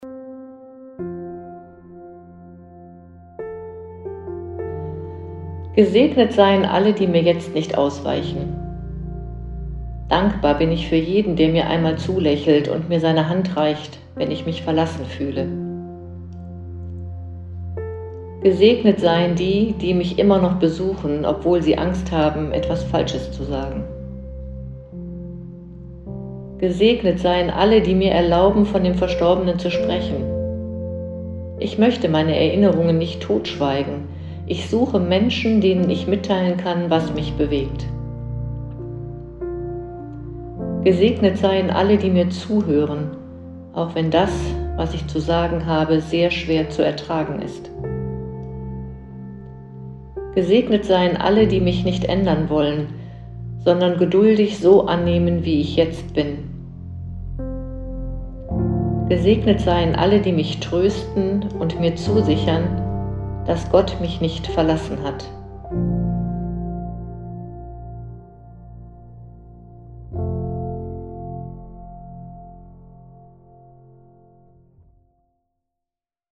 Gesprochene Texte zum Thema Trauer